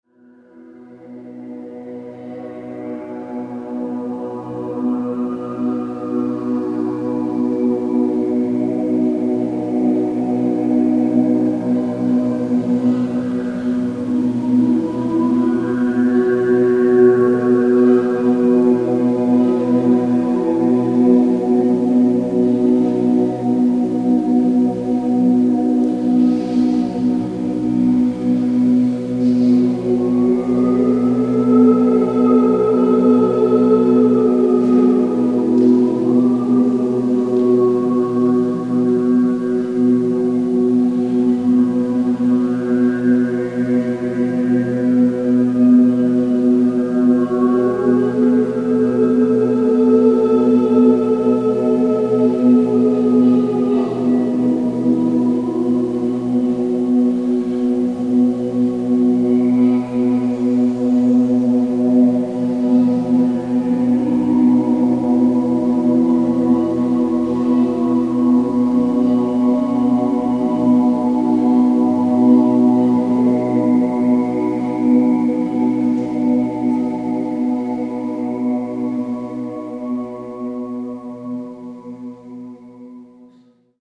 (Obertonchor-Improvisation)